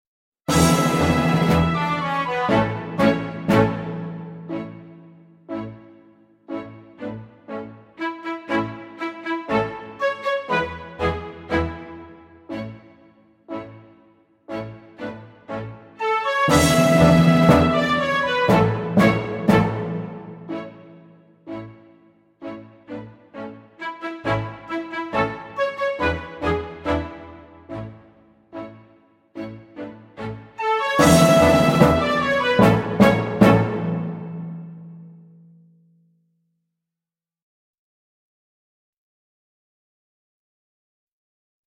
VS William Tell (backing track)